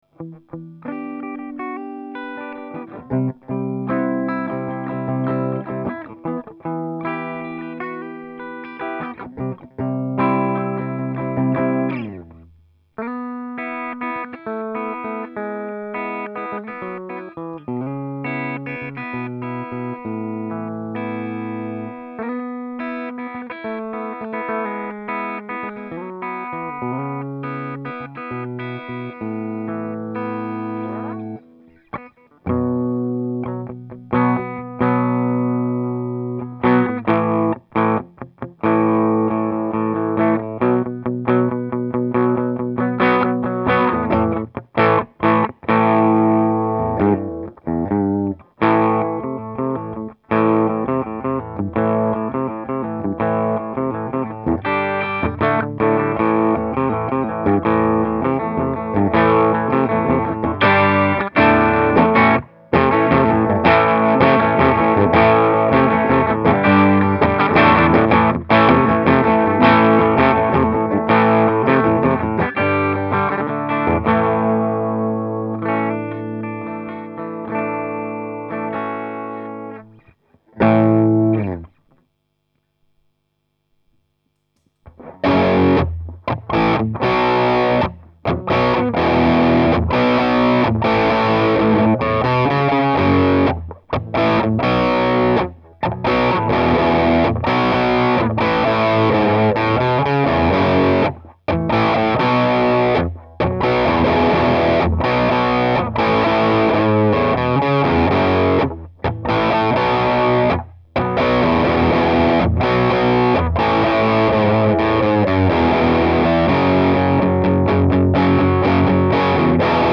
[VENDU] VHT Pittbul Superthirty - Ampli guitare - Page 3